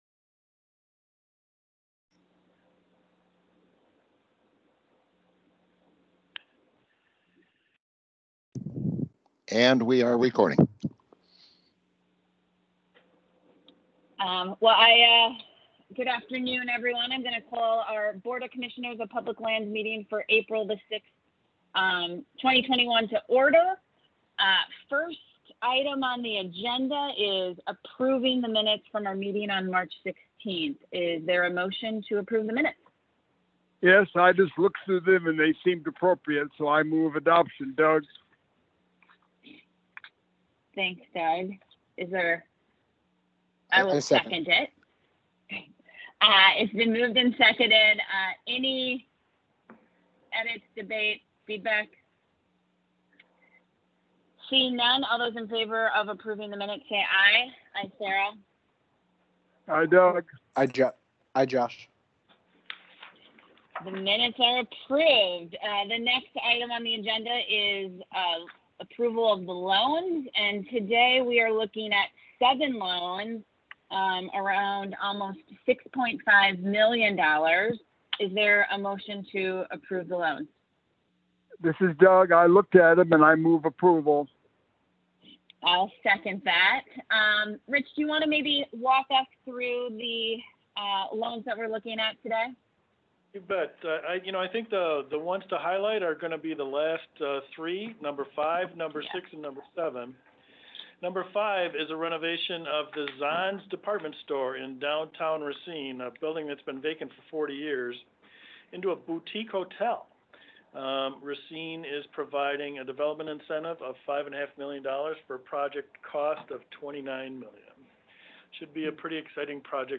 Listen to first January meeting recording